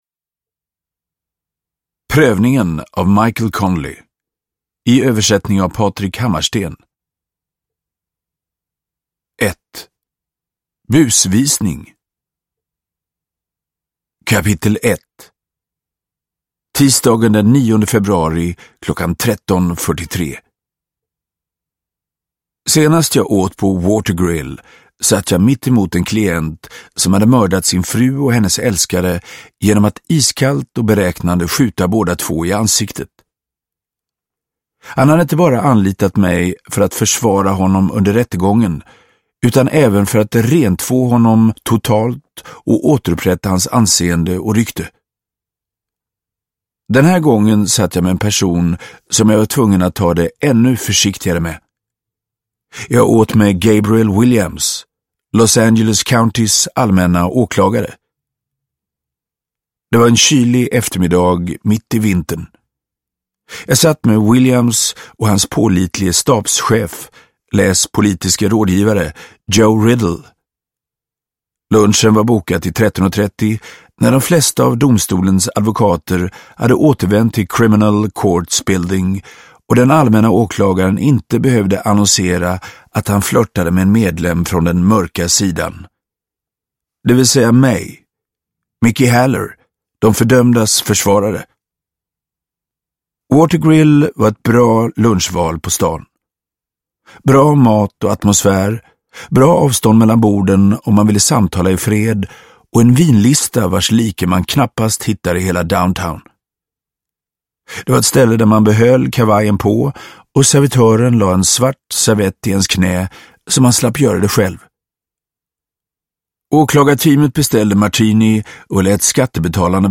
Prövningen – Ljudbok – Laddas ner
Uppläsare: Magnus Roosmann